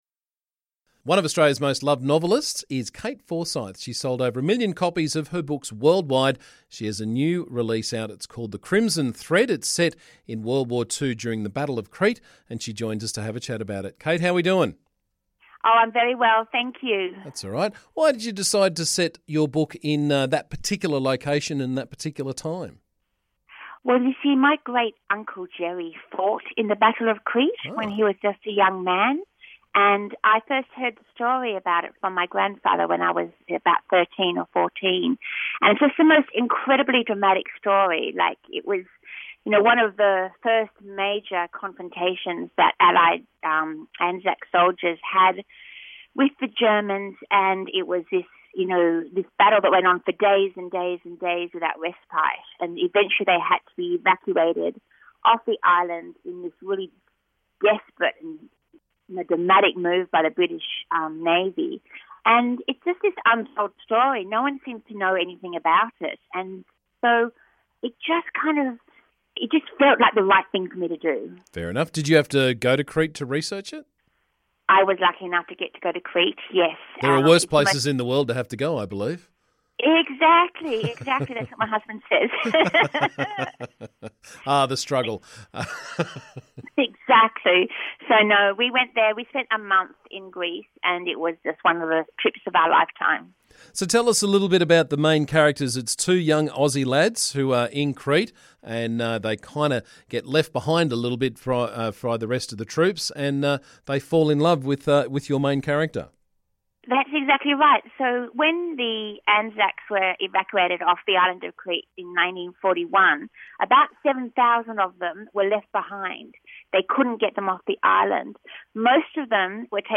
One of Australia's great fiction writers, Kate Forsyth, has a new book out and she joined us this morning to tell us all about it.